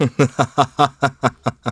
Riheet-Vox_Happy2.wav